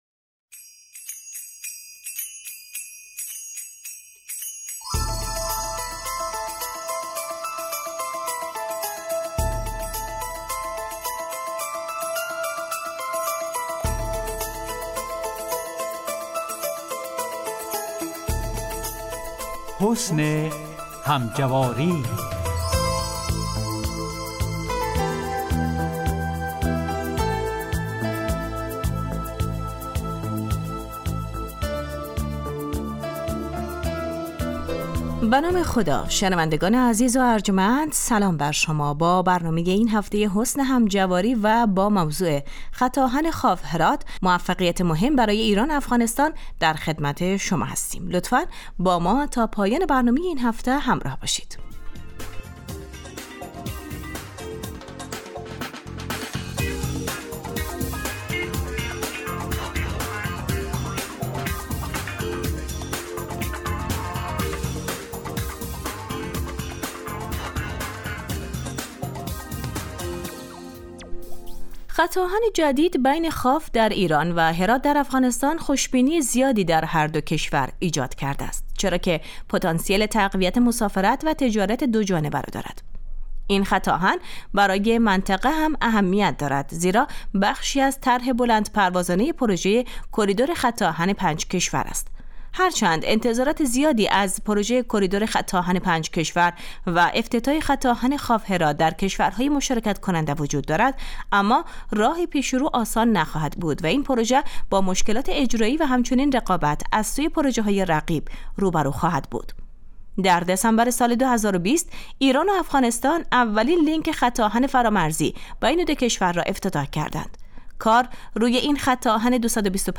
حسن همجواری برنامه ای 15 دقیقه ای است که در روزهای سه شنبه و پنج شنبه ساعت 14:15 به روی آنتن می رود .